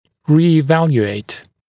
[ˌriːɪ’væljueɪt][ˌри:и’вэлйуэйт]переоценить, повторно оценить